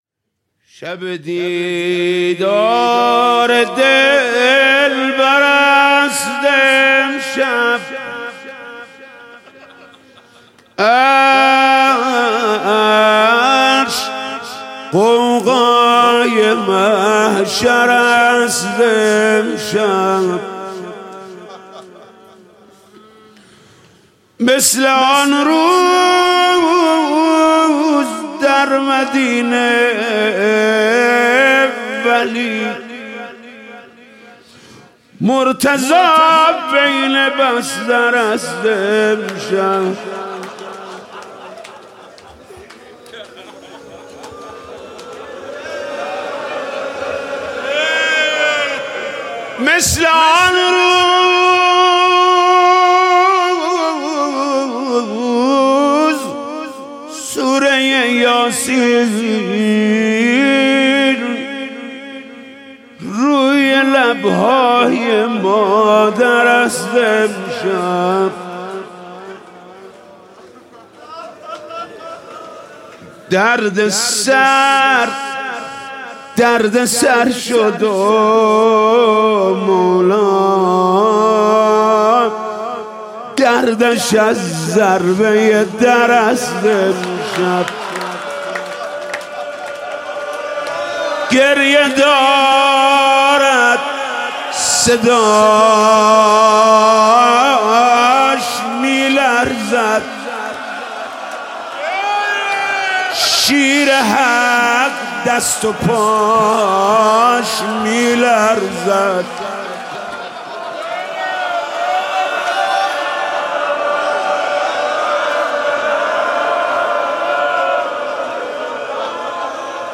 «شب بیستم» روضه: شب دیدار دلبر است امشب